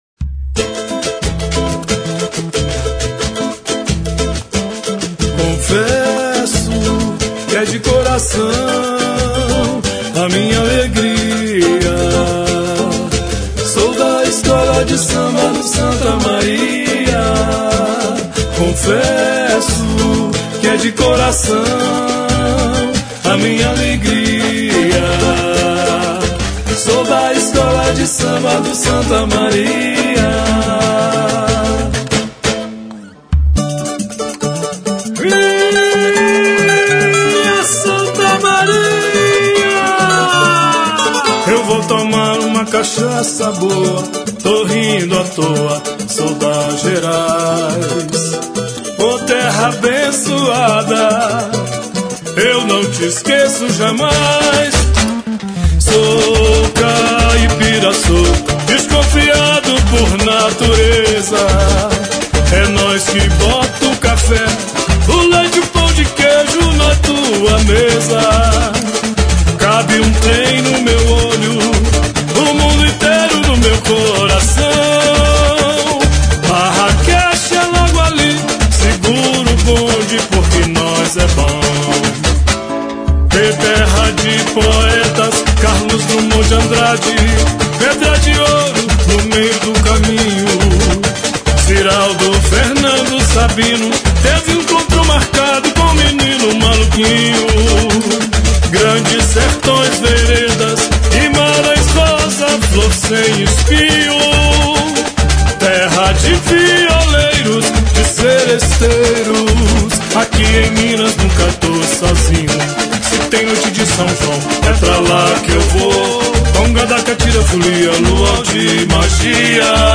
Santa Maria lança seu samba-enredo 2017 - barrosoemdia
A Escola de Samba Santa Maria, através de sua Associação Cultural, lançou no dia 22 de janeiro, no Ceclans, o seu Samba-enredo oficial do Carnaval 2017.